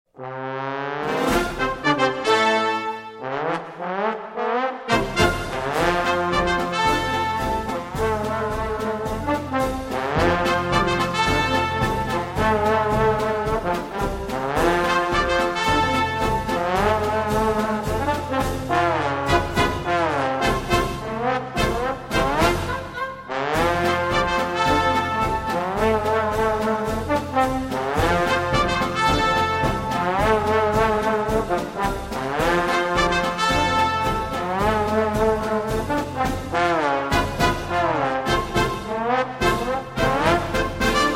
Brass Timbre test question 4